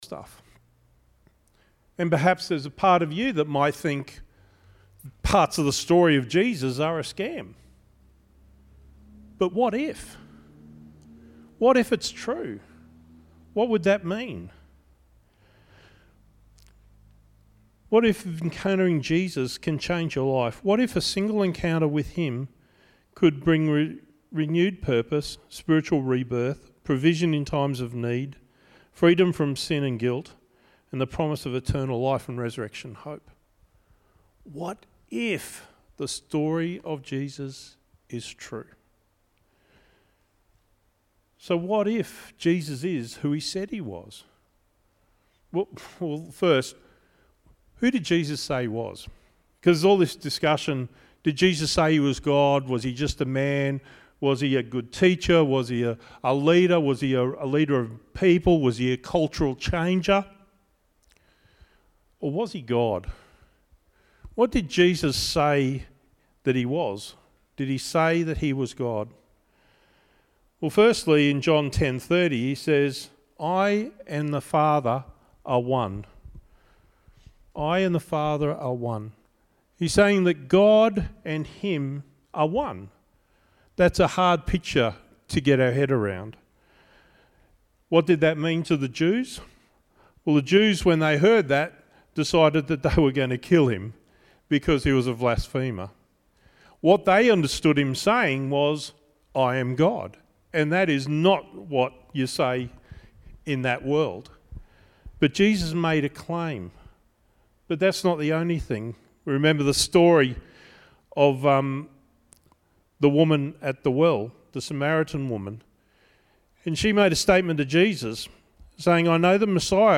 "What If" - Good Friday Service - Stanthorpe Baptist Church